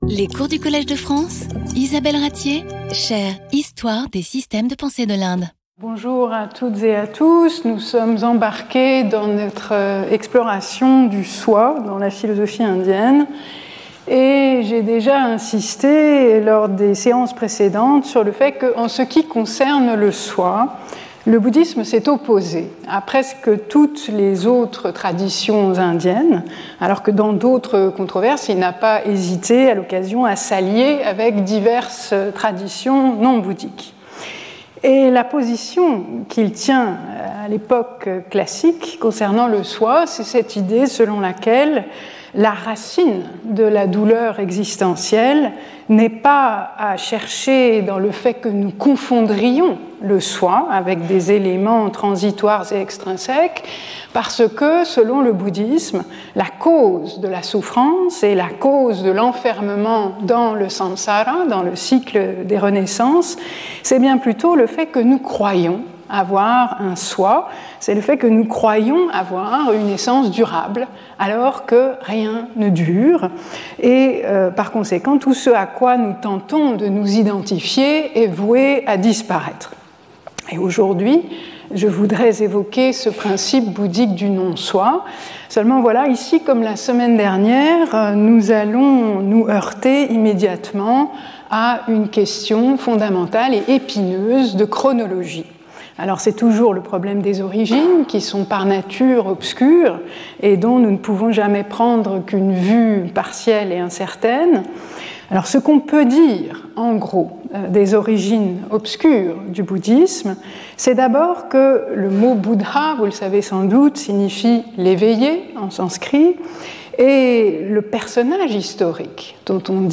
Lecture audio